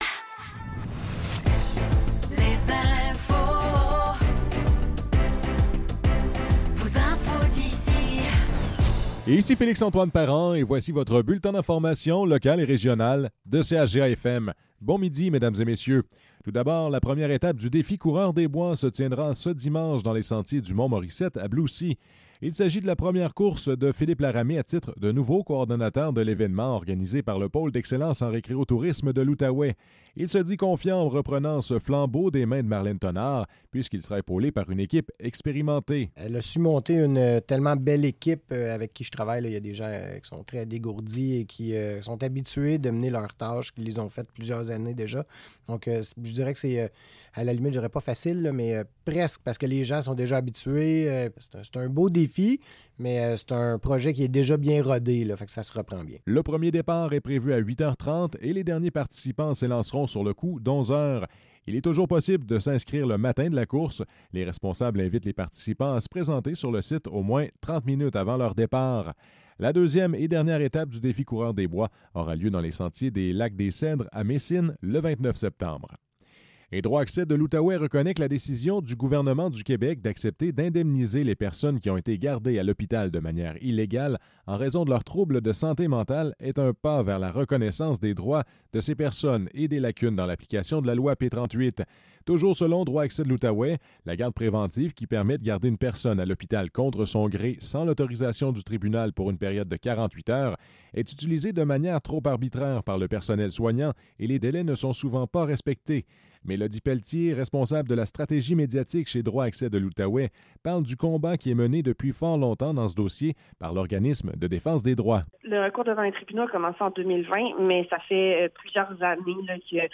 Nouvelles locales - 13 septembre 2024 - 12 h